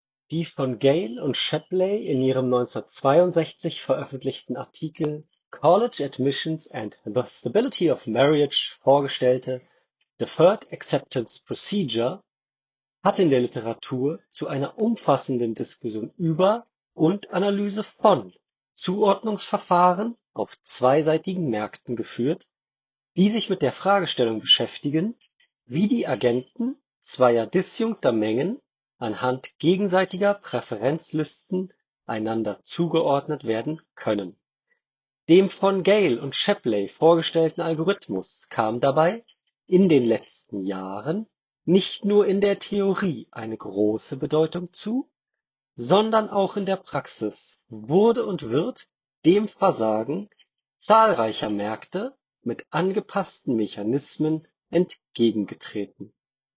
Die Telefonie über die Headphone (1) überzeugt hingegen nicht. Die Übertragung rauscht verhältnismäßig stark, es ist ein Echo zu hören und der Nutzer klingt blechern.
Nothing Headphone (1) – Mikrofonqualität